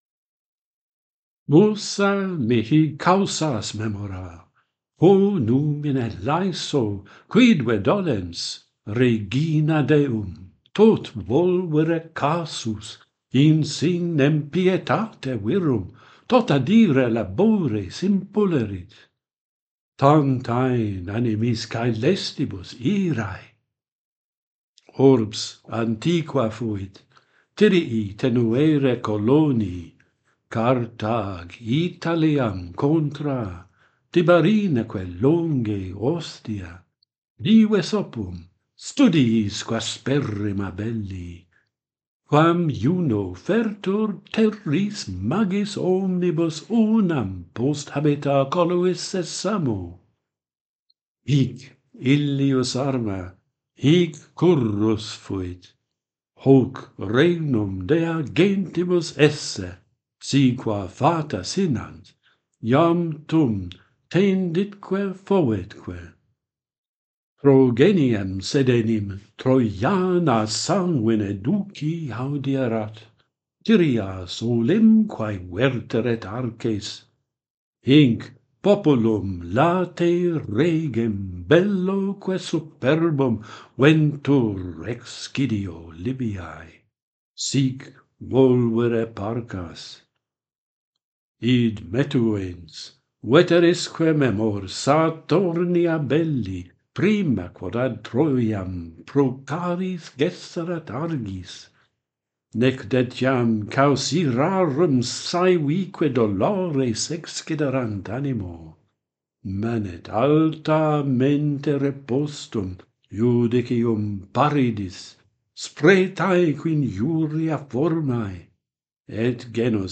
Juno's anger - Pantheon Poets | Latin Poetry Recited and Translated